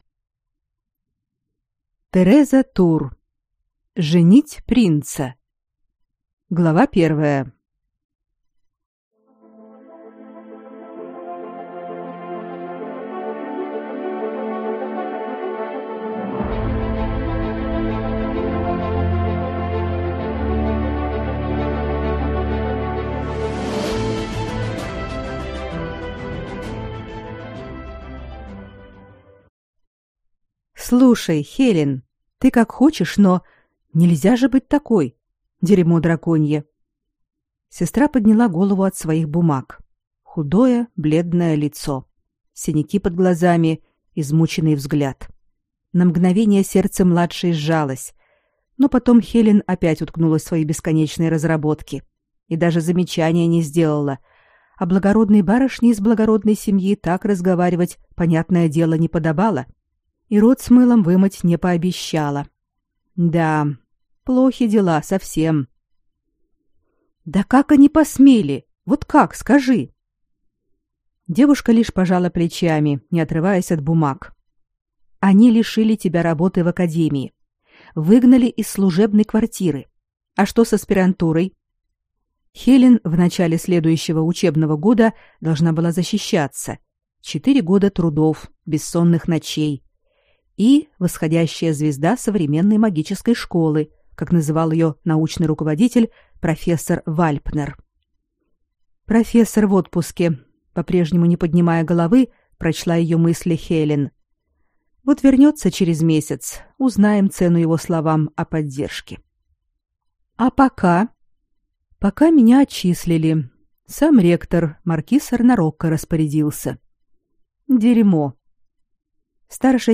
Аудиокнига Женить принца | Библиотека аудиокниг